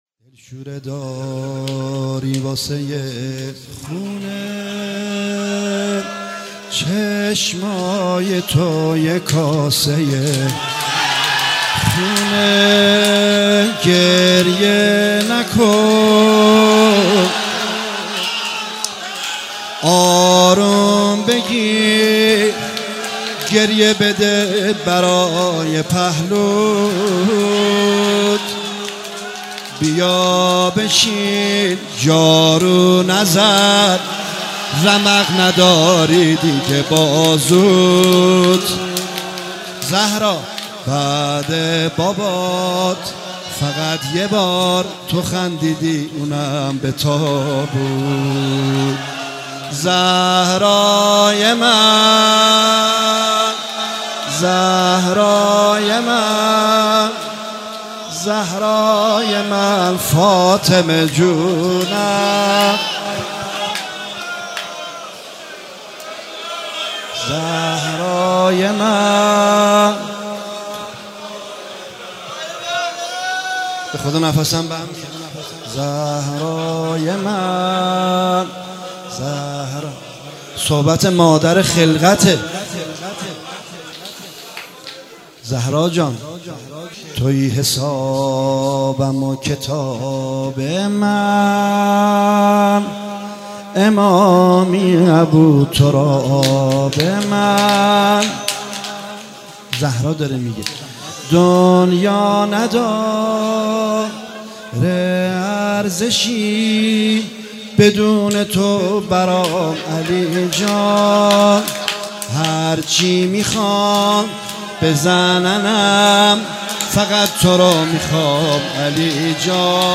مداحی زیبای «دلشوره داری واسه خونه» برای ایام فاطمیه